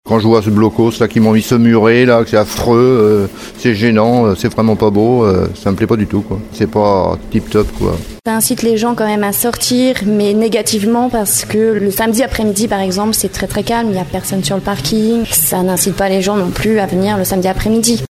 Rappelons que ce sont les piétons qui auront la priorité et que les véhicules motorisés seront limités à 20 km/h. Après 3 mois dans la poussière, nous sommes allés ce matin à la rencontre des commerçants de l’avenue de la Libération, globalement peu satisfaits des nouveaux aménagements et de l’évolution.